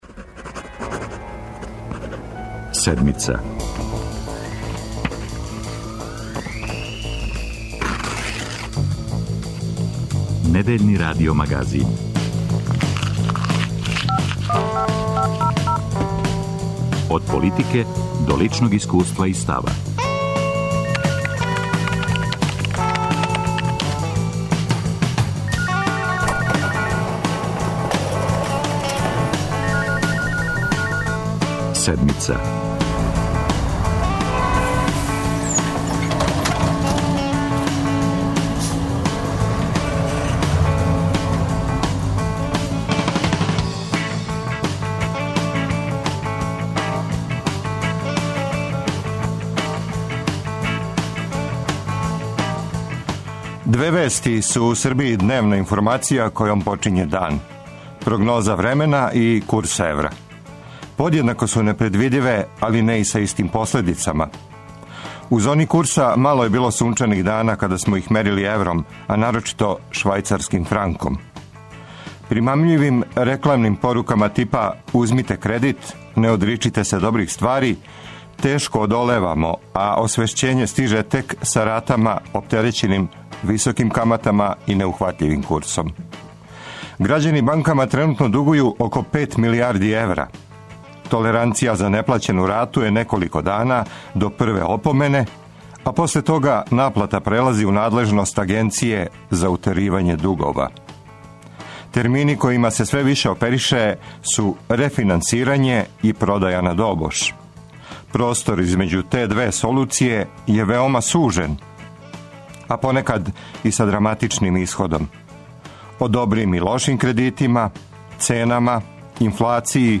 О овим и другим питањима говориће у Седмици Јоргованка Табаковић, гувернер Народне банке Србије.